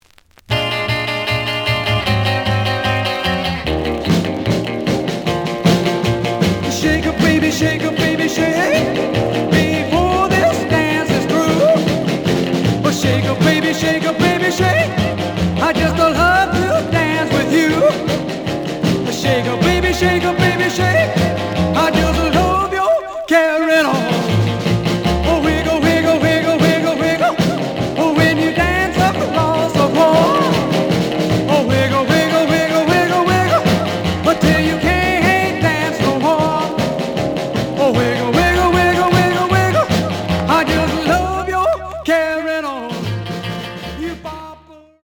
試聴は実際のレコードから録音しています。
●Genre: Rhythm And Blues / Rock 'n' Roll
盤に若干の歪み。